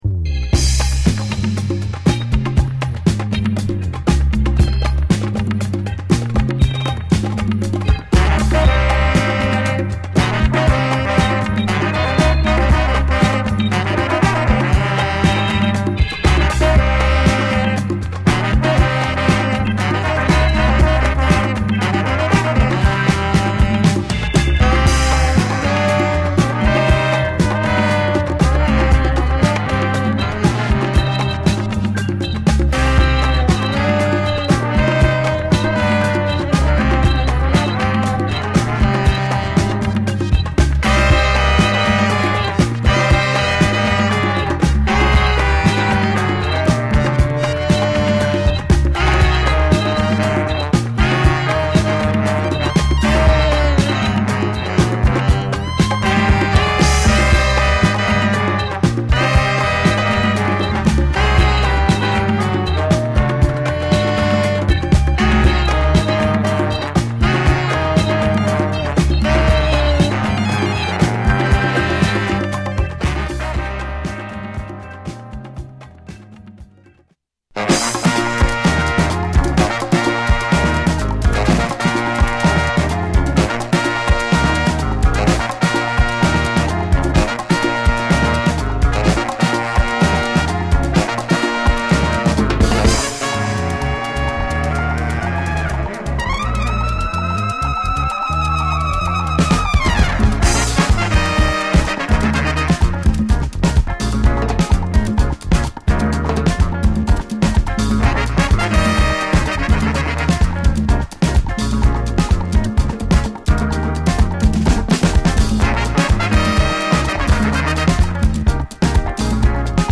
エチオ・ジャズなフレーズを奏でるホーン隊が、ダンサンブルなアフロ・ジャズ・ファンク・グルーヴと融合するA
ジャズファンクテイストも感じさせる、洗練された疾走感溢れるパーカッシブなアフロ・グルーヴB